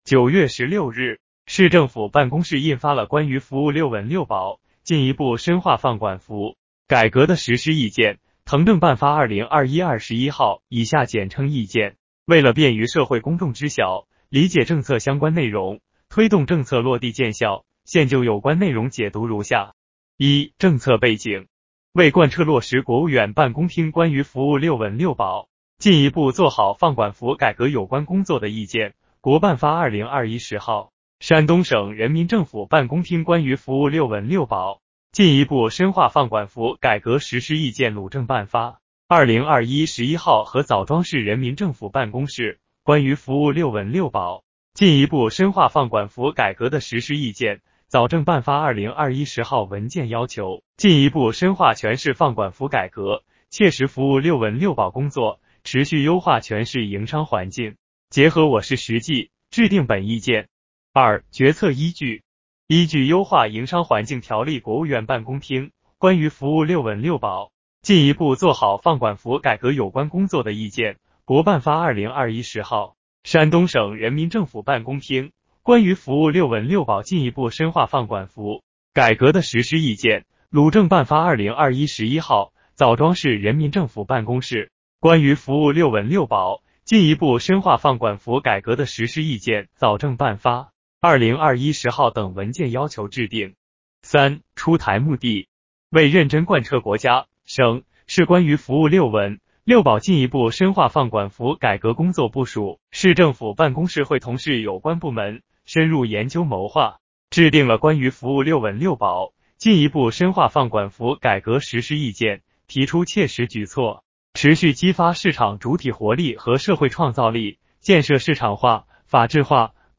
主题分类： 音频解读